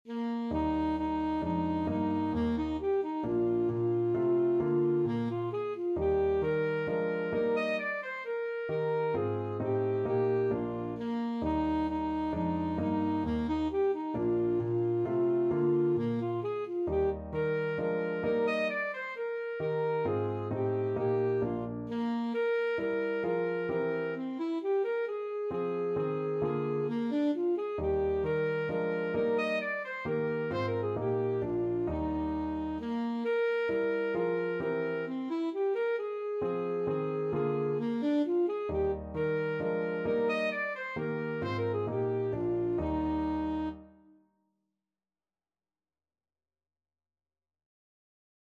Alto Saxophone version
Alto Saxophone
= 132 Allegro (View more music marked Allegro)
3/4 (View more 3/4 Music)
Classical (View more Classical Saxophone Music)